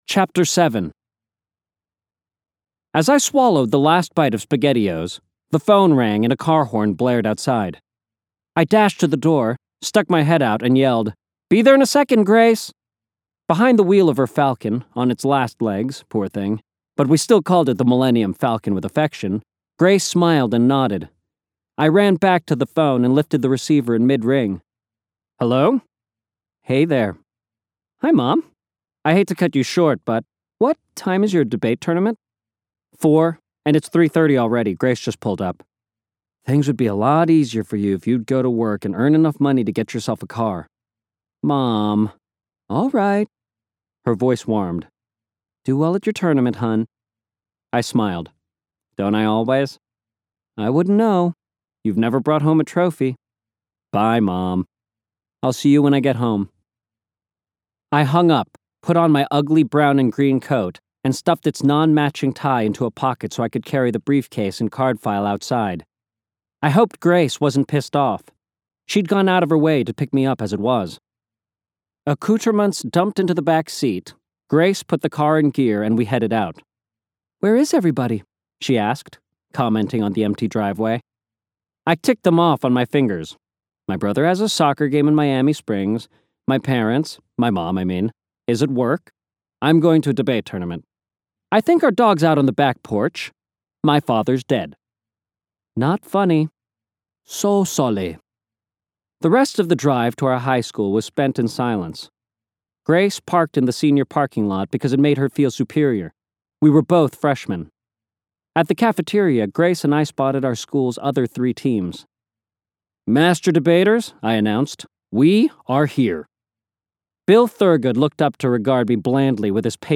ARIEL Audiobook Samples